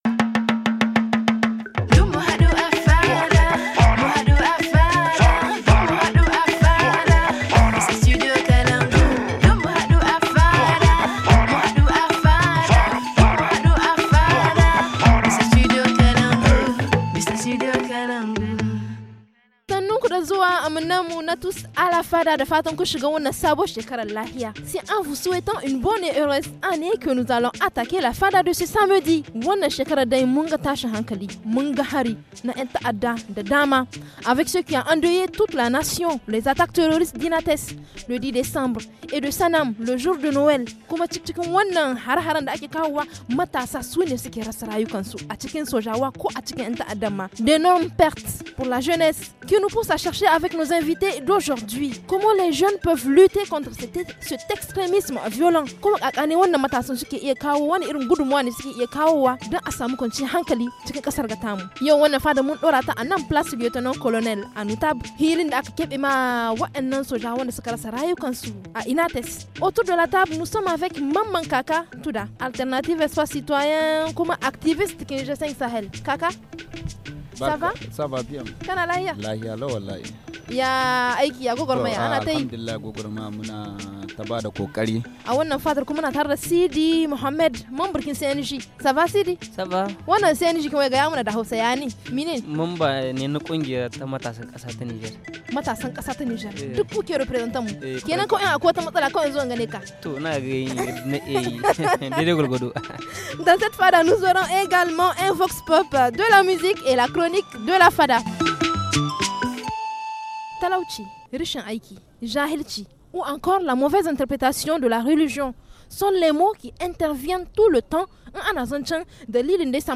D’énormes pertes, notament au sein de la jeunesse fortement impliquée dans ces conflits, qui poussent a cherché avec nos jeunes invités de la Fada, comment les jeunes peuvent lutter contre cet extrémisme violent ? Installés à la place lieutenant-colonel Anoutab